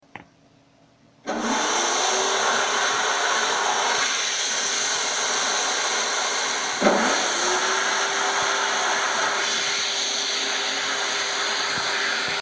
Aspiradora en funcionamiento
Me gusta Descripción Grabación sonora en la que se escucha como alguien pone en funcionamiento una aspiradora para limpiar con ella. Sonidos cotidianos